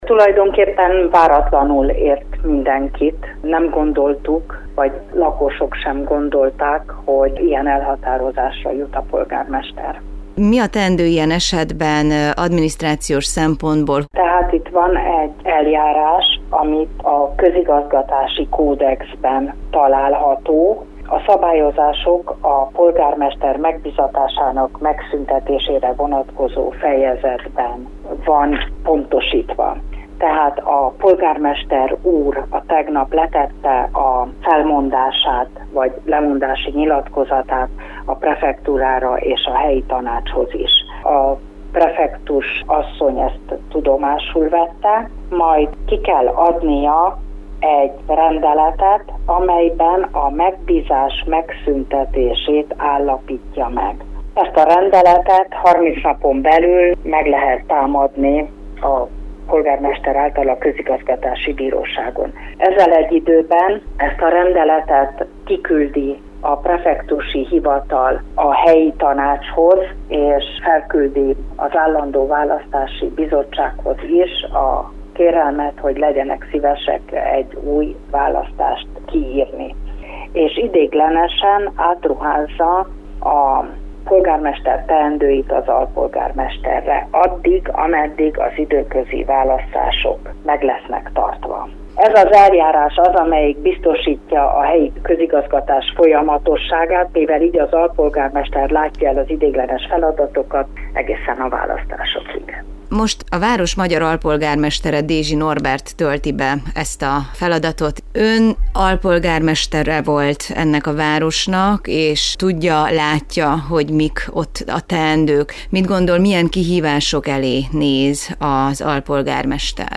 Dézsi Norbert alpolgármesterrel beszélgettünk azután, hogy a város elöljárója benyújtotta lemondását.